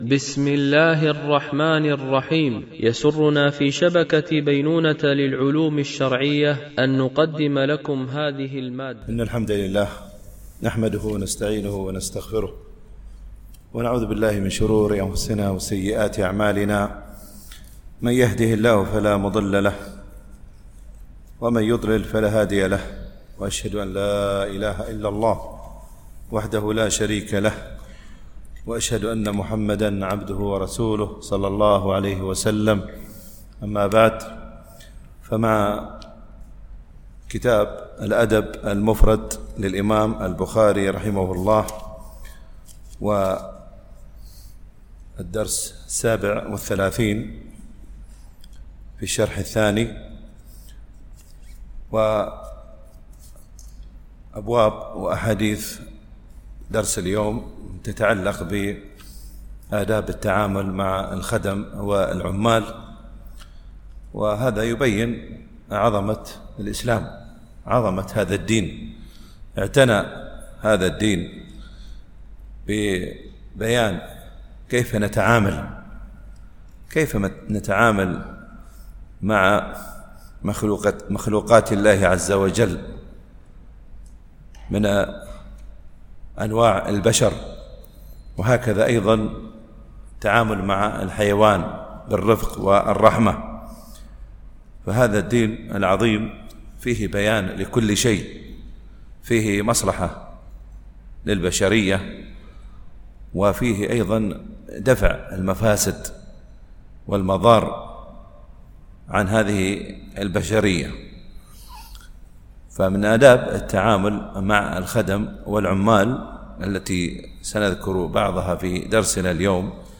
الشرح الثاني للأدب المفرد للبخاري - الدرس 38 ( الحديث 156 - 160 )